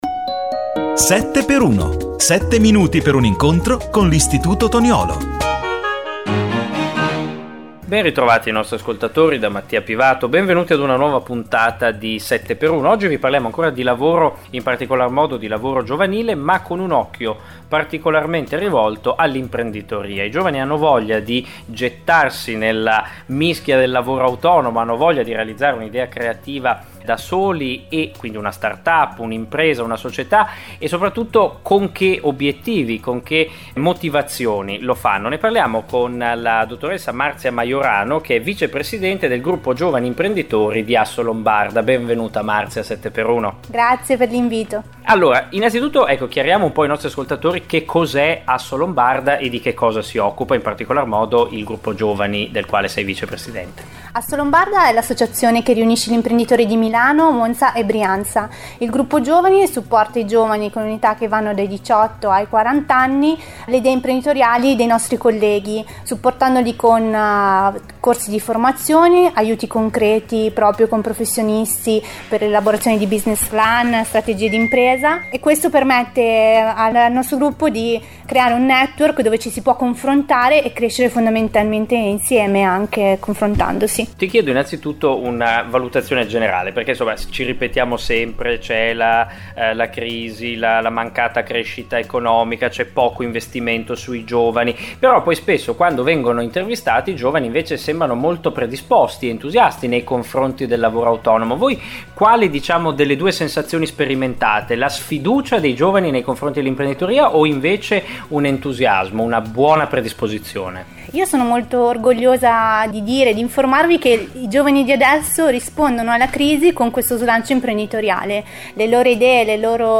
7×1 Giovani e lavoro Ecco la puntata intera andata in onda il 5 novembre su Radio Marconi.